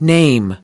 15.Name /neɪm :tên